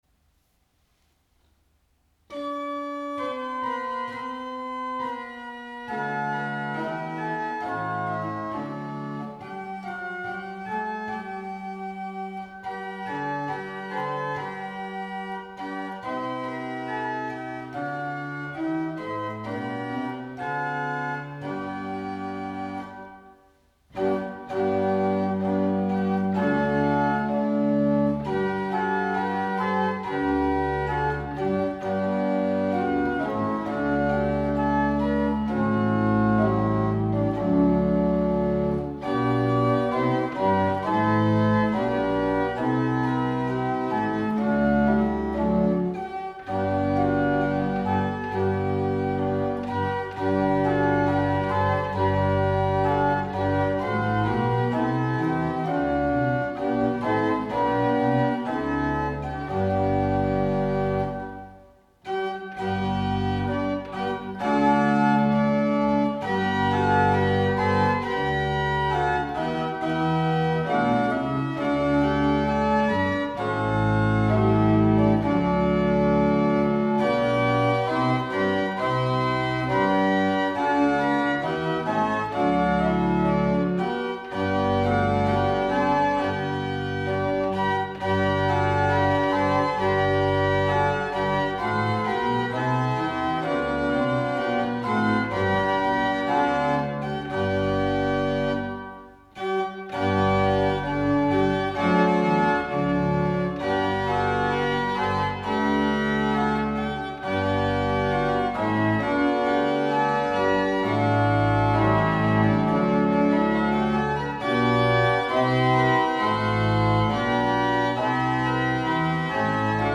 Choräle für Advent und Weihnachtszeit an Orgeln des Kirchenkreises